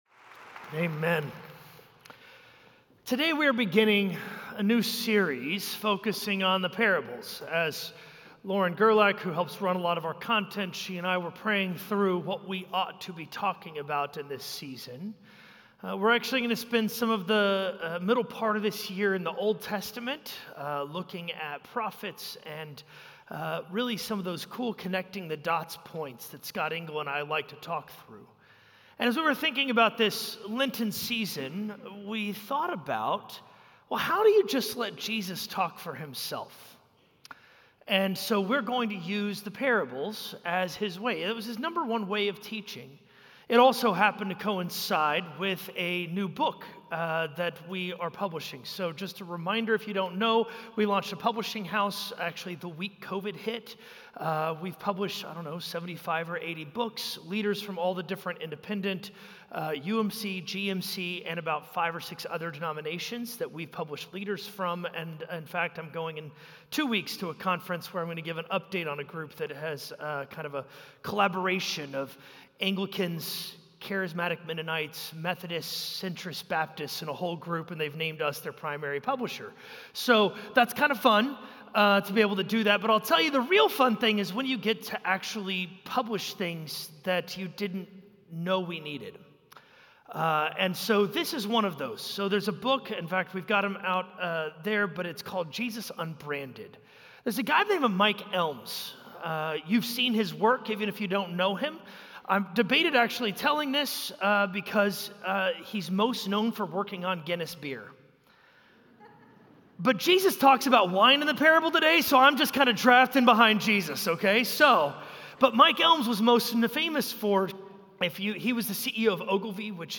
A message from the series "Jesus Parables."